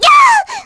Viska-Vox_Damage_kr_01.wav